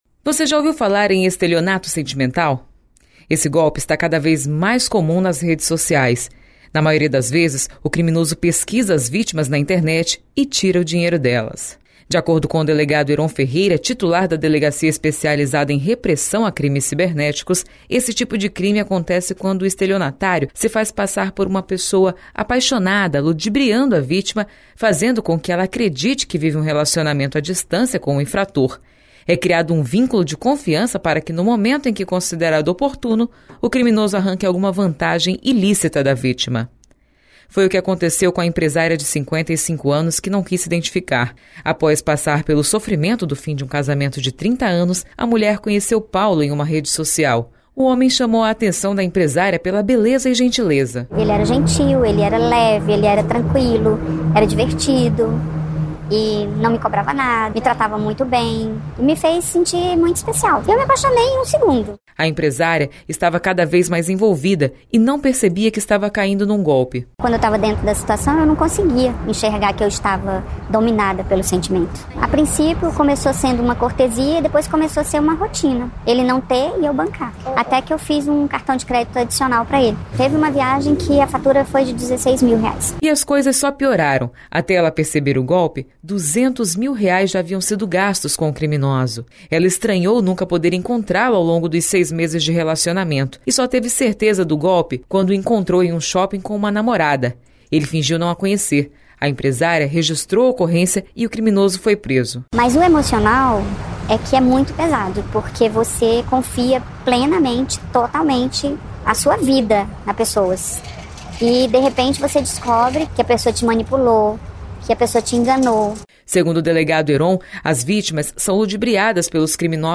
Saiba como esse golpe acontece, na reportagem